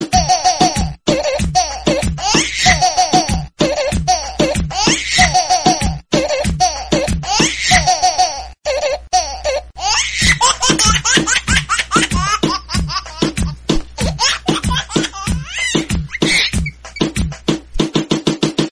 Kategorien: Lustige